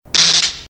• ELECTRICITY CRACKLES.mp3
Power generator has a big discharge, as the crackles are surrounding the microphone.
electricity_crackles_5lg.wav